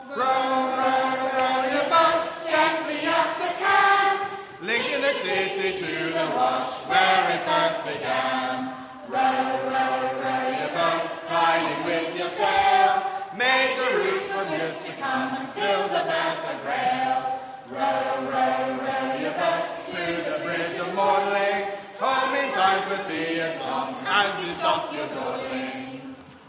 Singing History Concert 2016: To the Wash 1